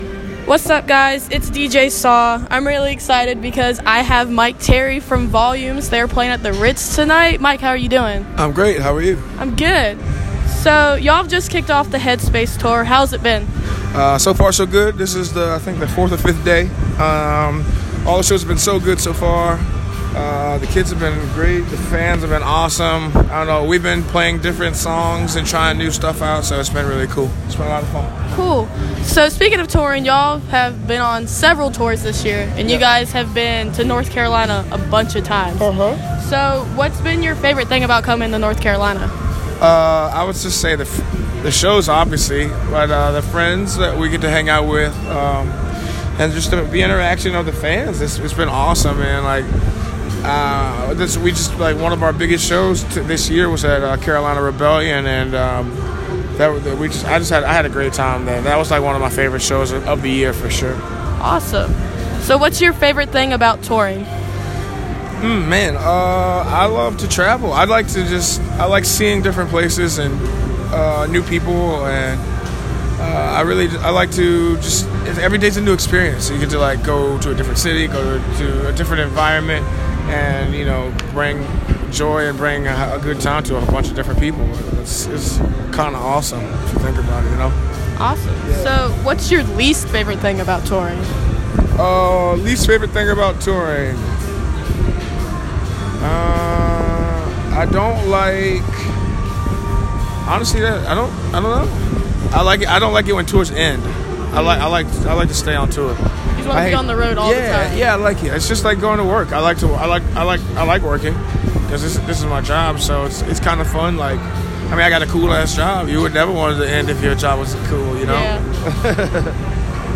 On Friday, September 29th, I went to see Issues, Volumes, Too Close To Touch, and Sylar at the Ritz.